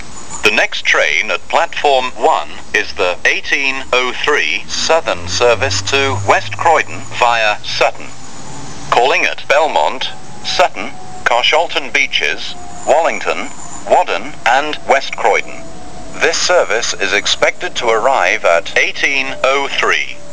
Train information at Banstead
Train information for a delayed service at Epsom Downs